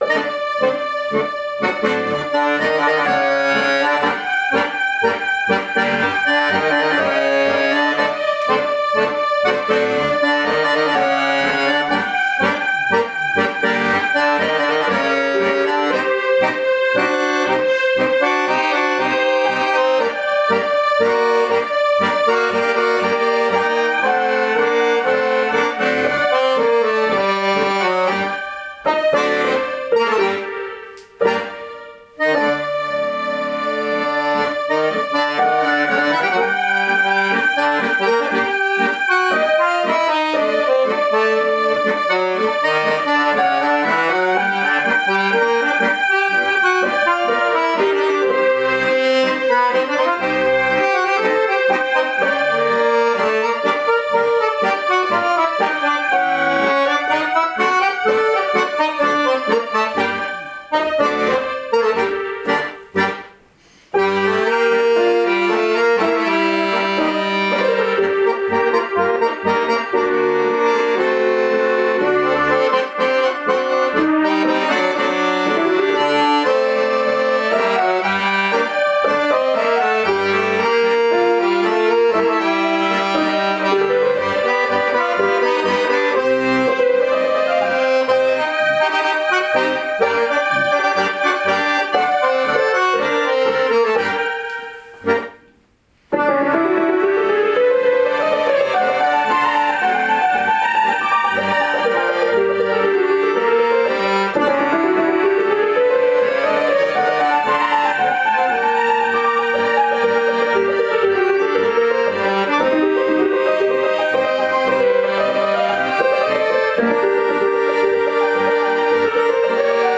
Tochter ((Sopran / Klavier) und Vater (Tenor / Akkordeon)) singen zusammen internationale Lieder - Chansons, Volkslieder, Schlager, Evergreens, Arien in mehreren Sprachen - und spielen Melodien (Tango, Walzer, Lateinmusik, usw.) aus aller Welt.
• Akkordeon